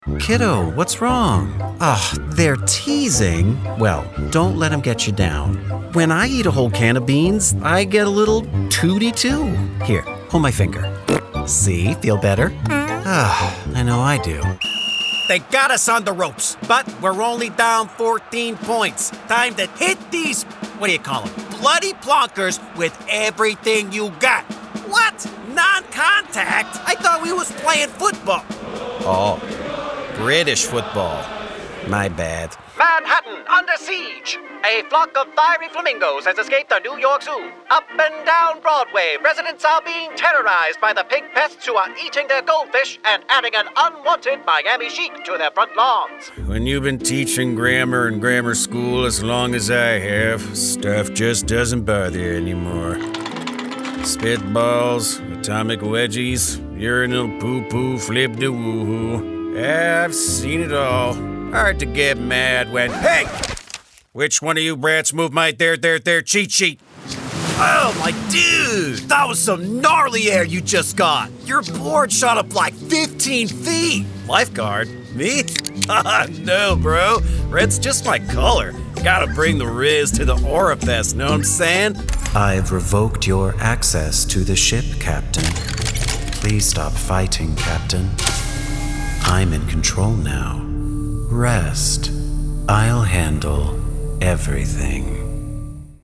ANIMATION VOICEOVER DEMOS
A world-class, commercial grade studio in North Hollywood, CA is where all my recording takes place.